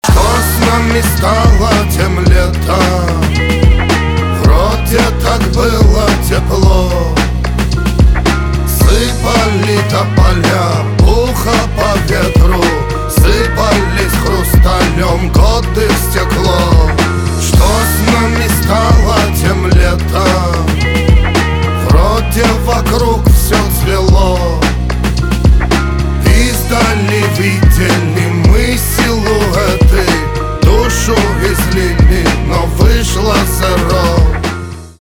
русский рэп
чувственные
пианино , грустные